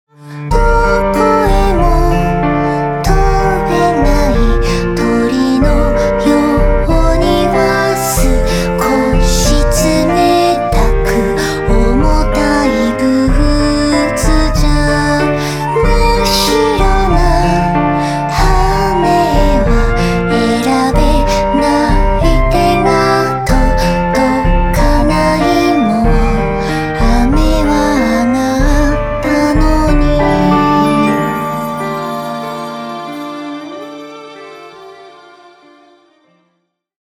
チェロ演奏